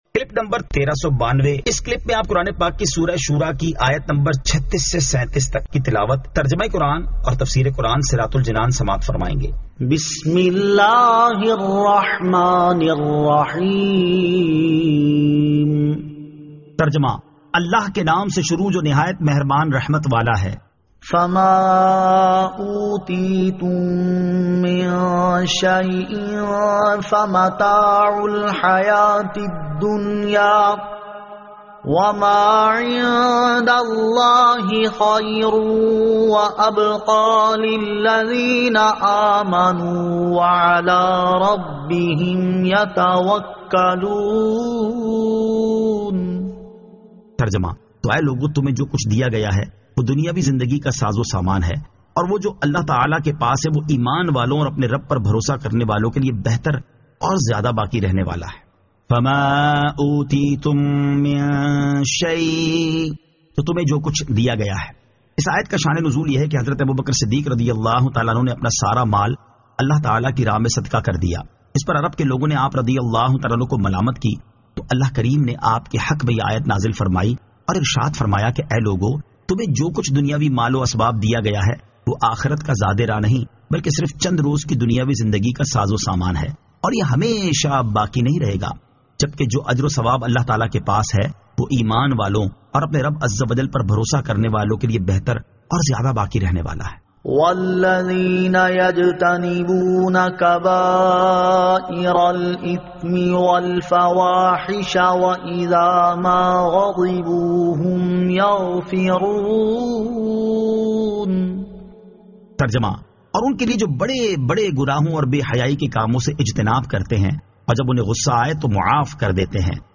Surah Ash-Shuraa 36 To 37 Tilawat , Tarjama , Tafseer